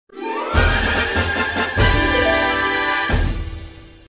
Звуки завершения, эффект
Звук означающий что все закончилось